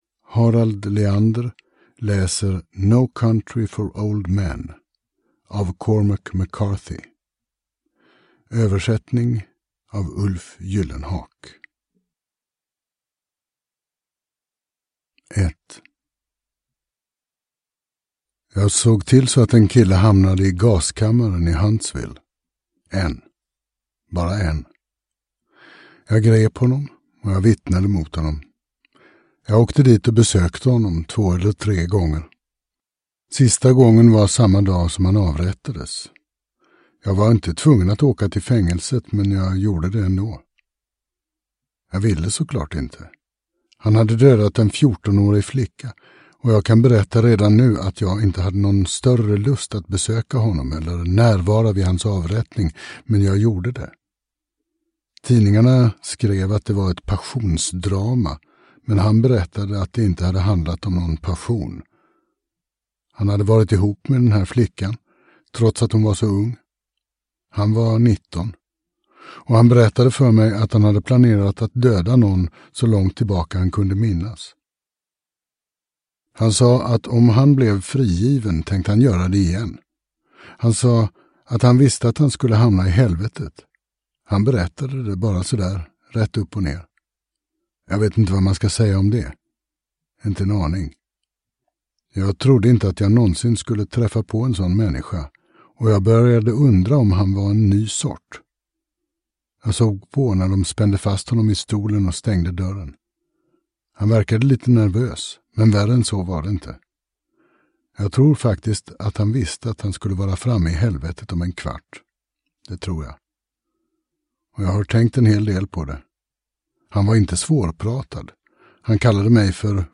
No country for old men – Ljudbok – Laddas ner